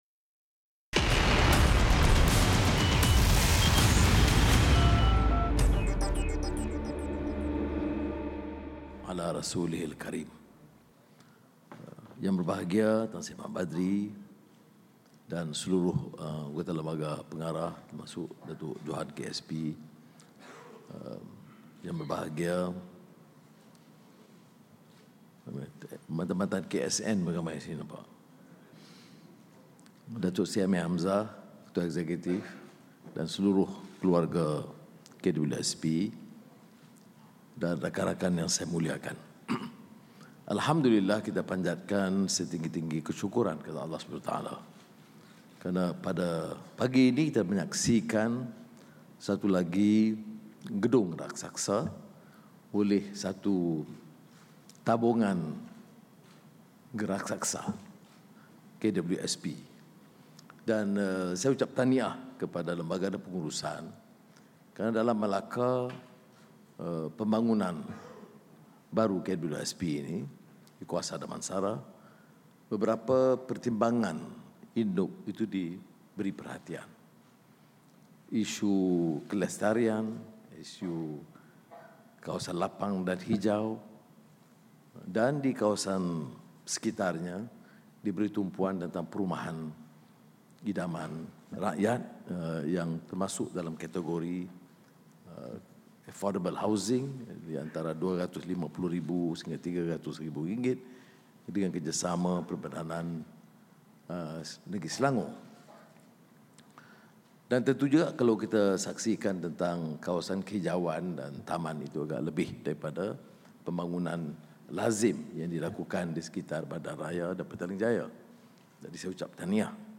Perdana Menteri, Datuk Seri Anwar Ibrahim hadir Majlis Perasmian Menara KWSP Kwasa Damansara di Dewan Perdana Menara KWSP Kwasa Damansara.